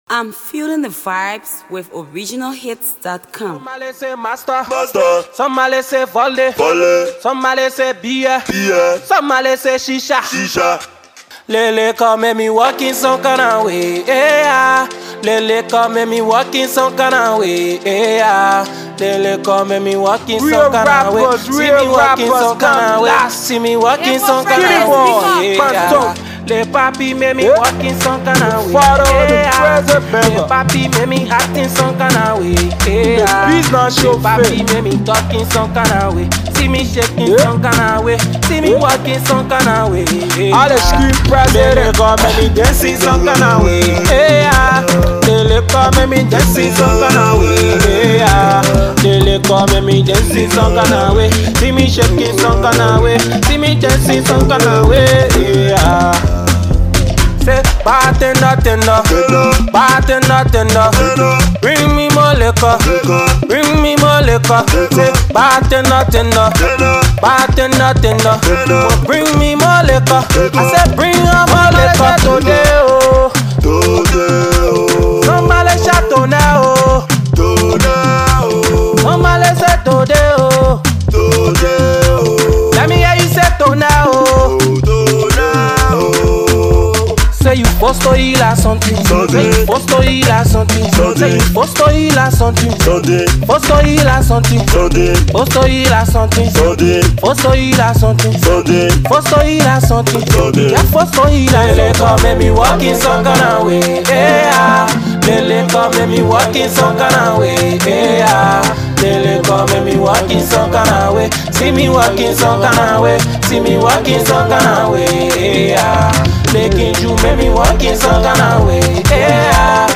Hipco Rapper
Hipco Music Trapco